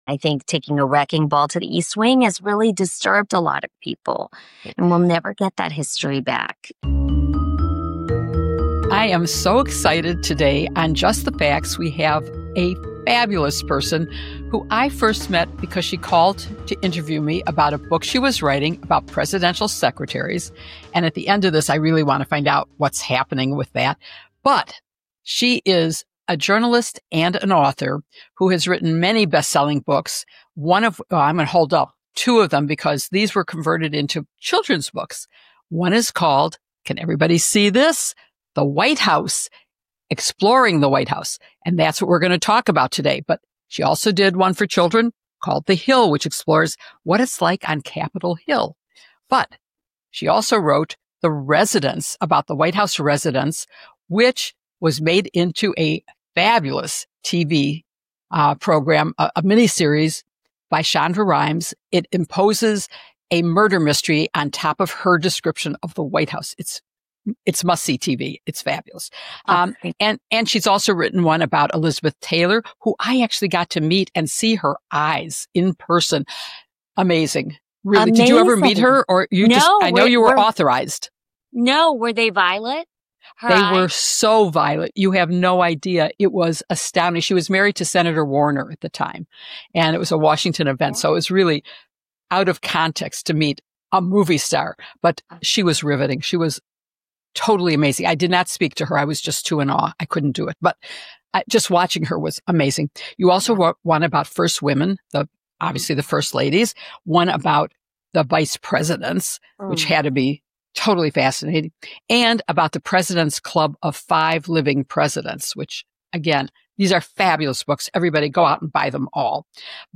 Jill Wine-Banks interviews Kate Andersen Brower, a renowned journalist and author The Residence, about the White House's history, layout, and recent changes, particularly the demolition of the East Wing.